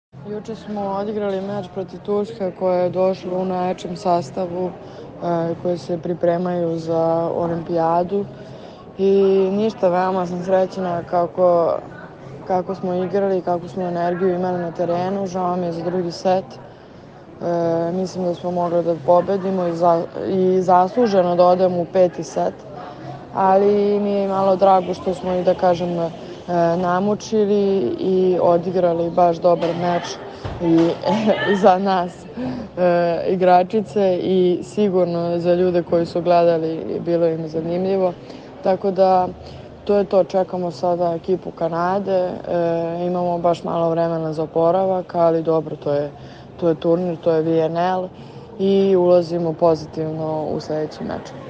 Izjava Katarine Lazović